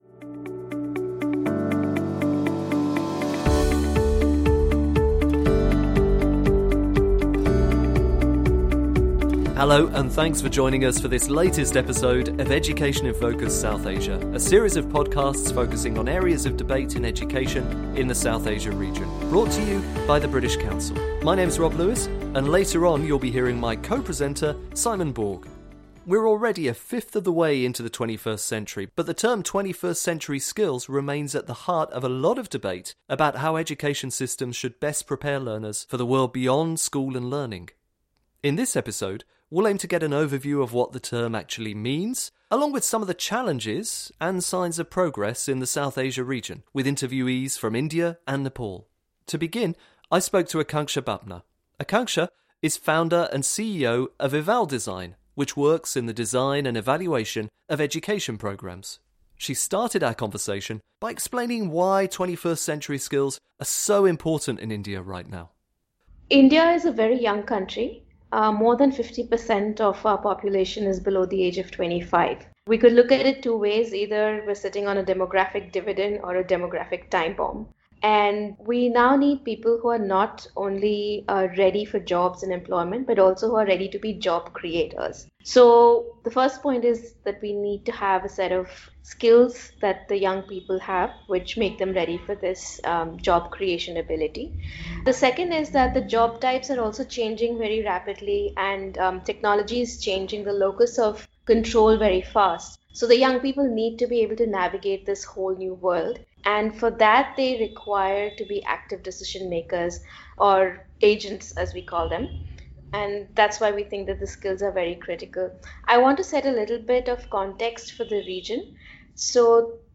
Interviewees in this episode explore why these skills are so important to the region, as well as some essential considerations when aiming to incorporate them into curricula.